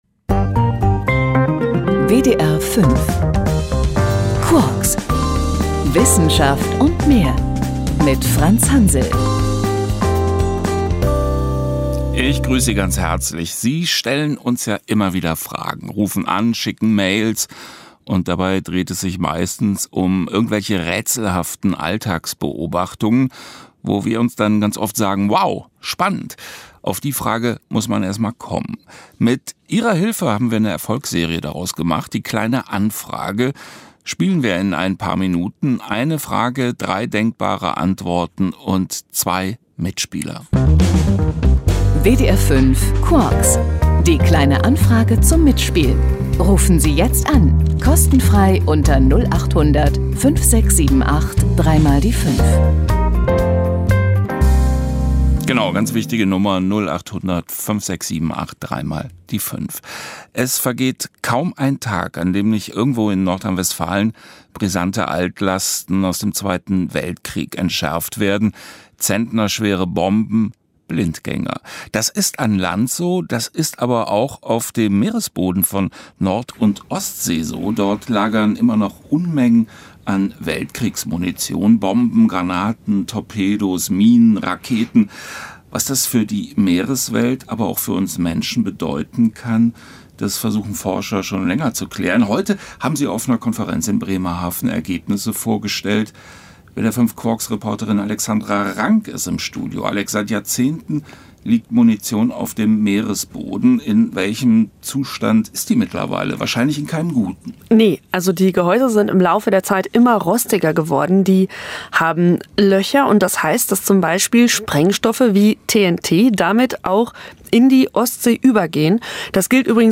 In einem Radiobeitrag stellt der WDR das medizinische Behandlungszentrum des Franz Sales Hauses vor.